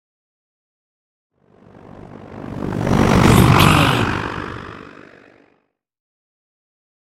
Scifi whoosh pass by chopper
Sound Effects
futuristic
pass by